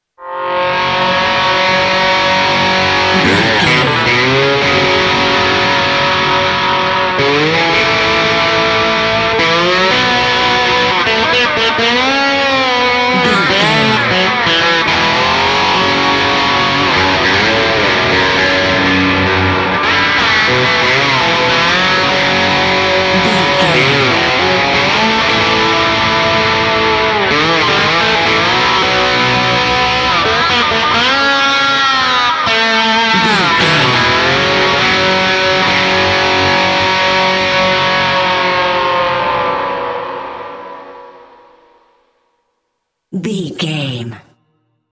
Ionian/Major
electric guitar
Southern Rock
blues rock
hard rock
driving
lead guitar
Slide Guitar
aggressive
energetic
intense